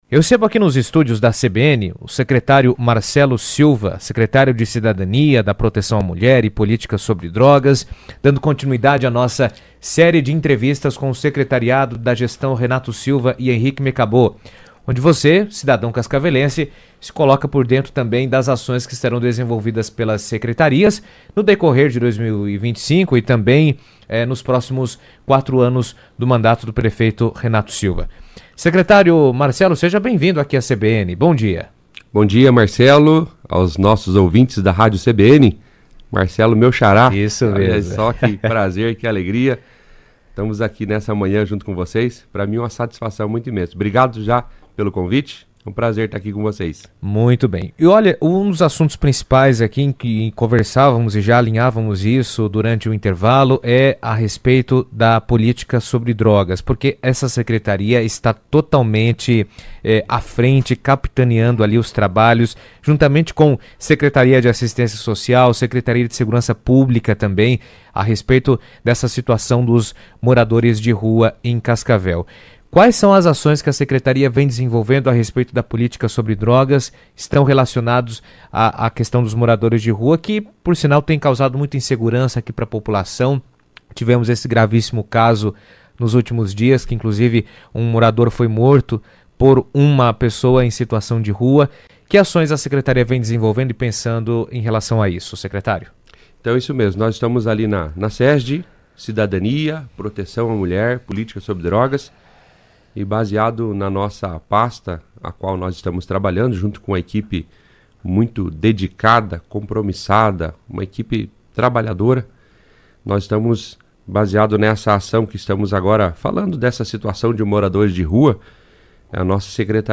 A CBN Cascavel deu continuidade a série de entrevistas com o secretariado da gestão Renato Silva.
Nesta sexta (04), foi a vez de Marcelo Silva - Secretário de cidadania, da proteção à mulher e política sobre drogas.